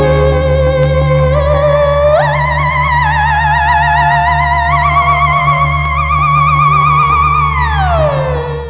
(A space ship powers down.)